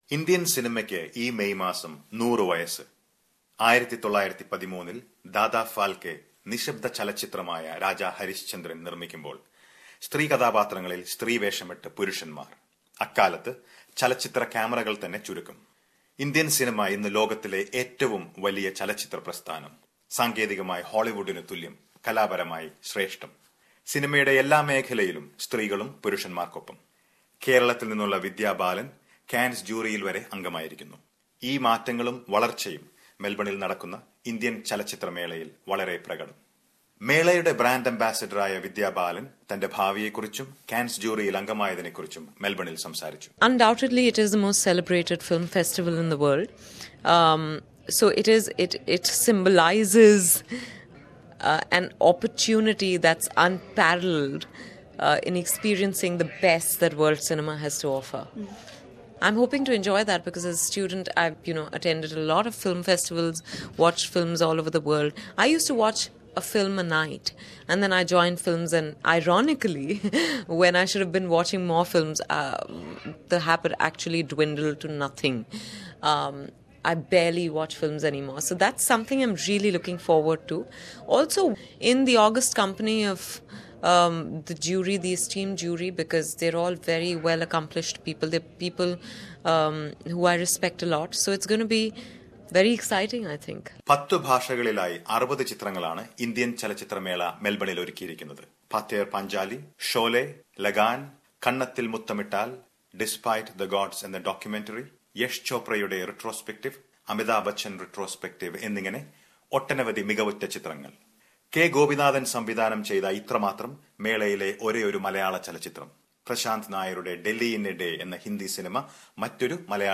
A report from the Indian Film Festival Melbourne, which celebrates the 100 years of Indian cinema.